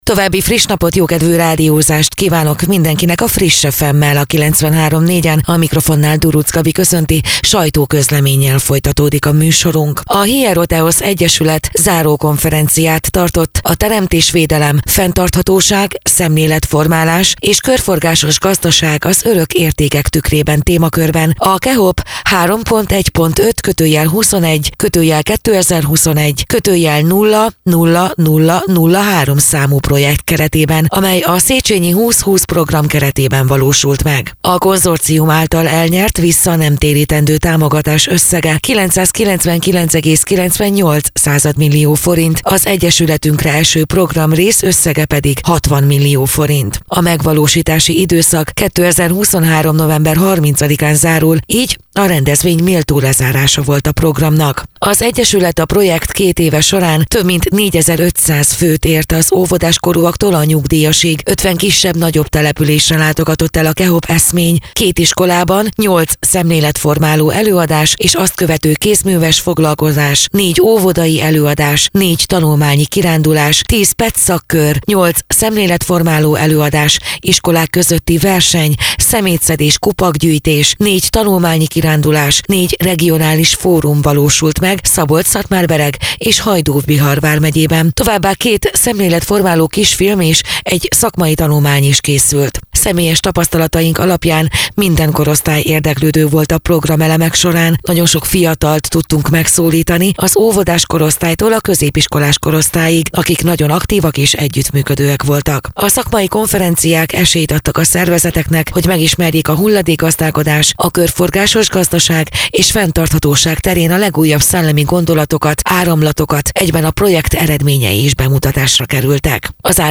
Rádió
2023. november 27.: Máriapócs - Teremtésvédelem:fenntarthatóság, szemléletformálás és körforgásos gazdaság az örök értékek tükrében - sajtóközlemény